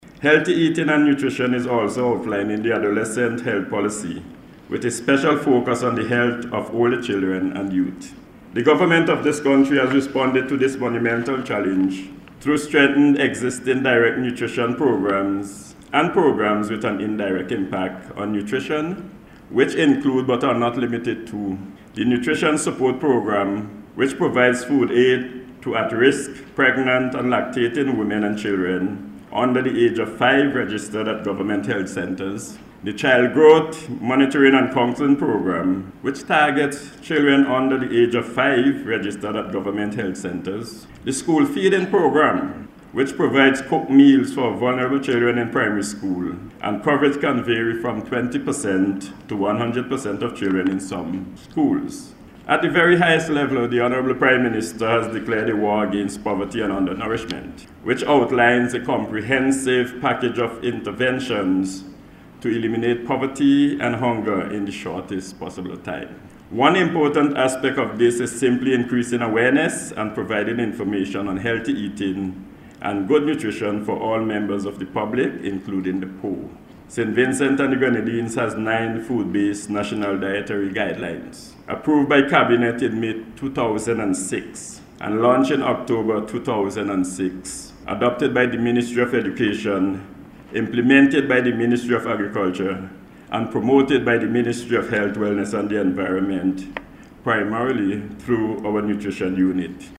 He was addressing hosted a one-day Symposium and Health Fair, held last week as part of activities to observe Nutrition Awareness Week.